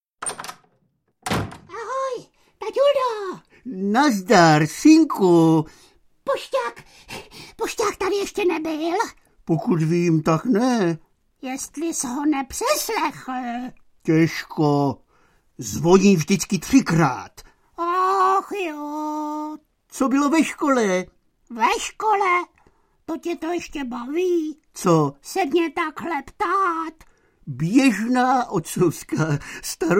Je to velmi zajímavá exkurze do slavné historie divadla S + H, ale také zvukových záznamů v českém jazyce, třeba ještě zprvu točených ve studiu v Berlíně. Dnešní naprostý fenomén v oblasti loutkového divadla a mluveného slova (celkem pět a půl milionu jen Supraphonem prodaných nosičů s hlasy ušatého otce, jeho synka, psíka a přátel) je tu zachycen v dějinných souvislostech.